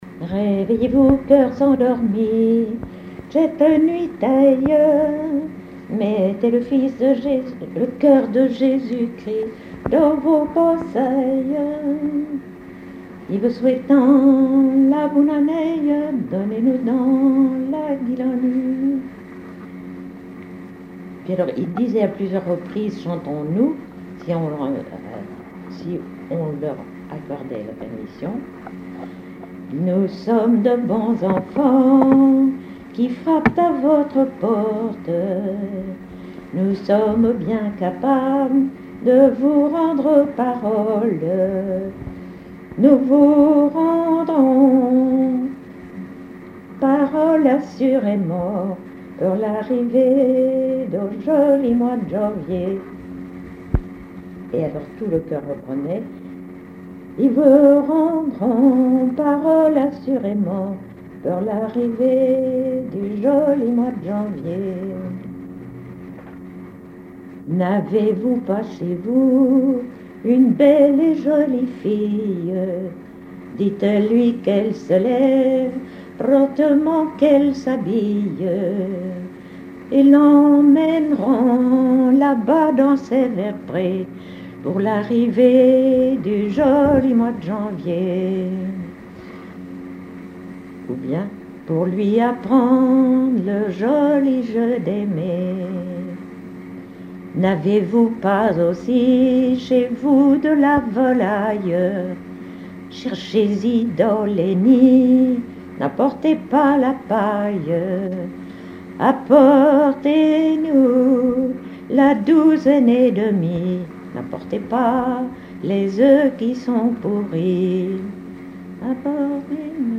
Usage d'après l'informateur circonstance : quête calendaire ;
Genre strophique
Catégorie Pièce musicale inédite